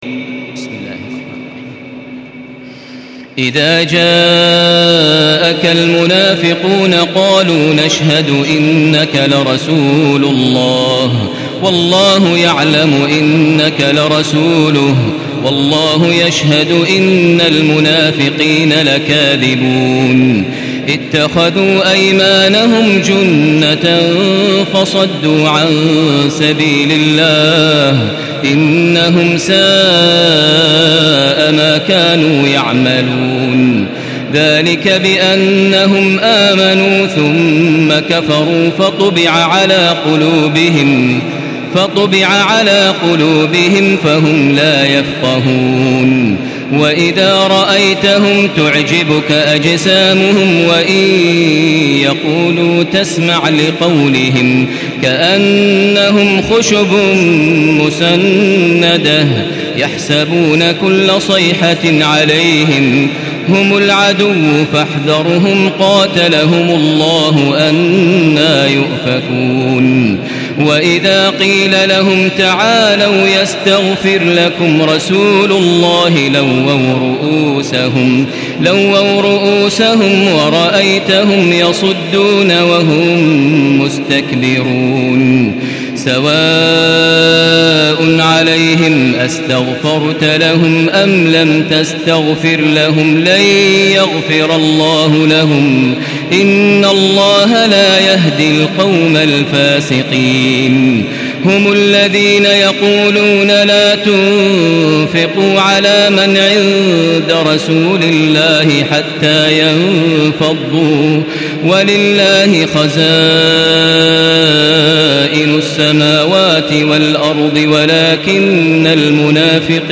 سورة المنافقون MP3 بصوت تراويح الحرم المكي 1435 برواية حفص
مرتل حفص عن عاصم